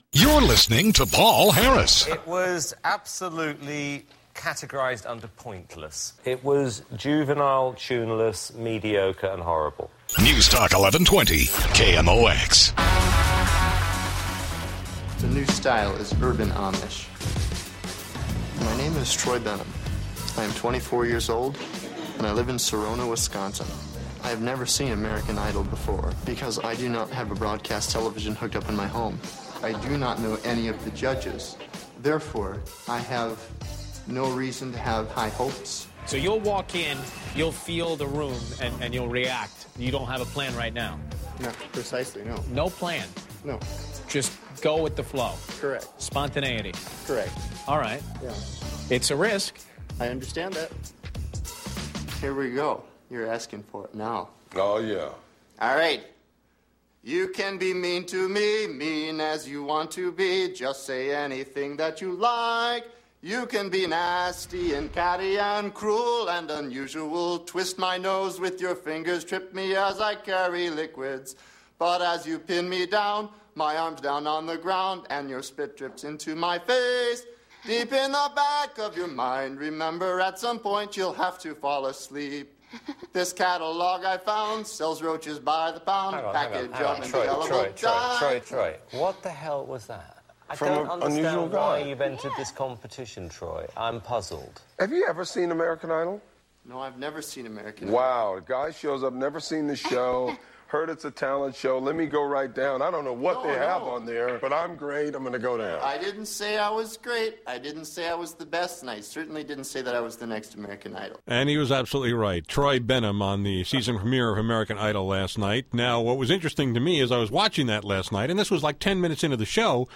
So I called Heywood today on my show to ask him how this all happened, whether they had to get his permission, and whether they’d have to send him a check.